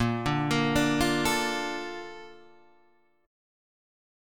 A# Major 7th